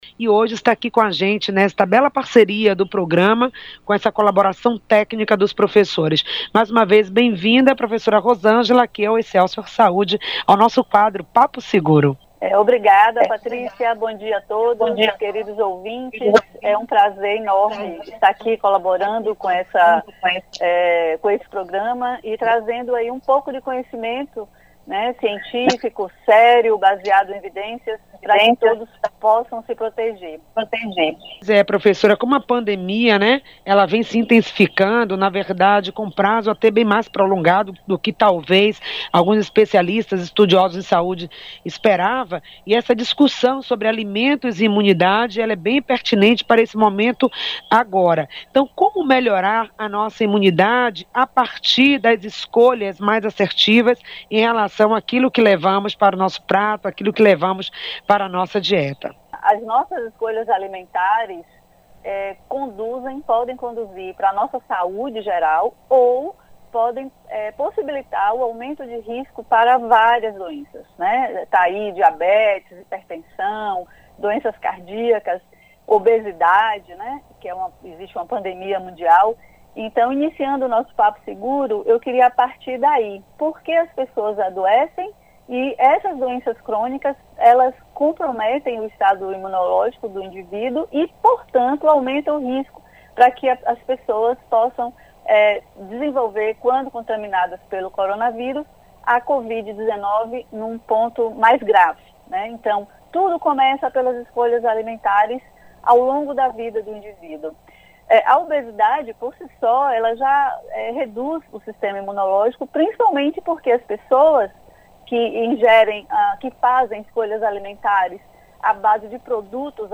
Papo seguro: Alimentos e imunidade – Entrevista 2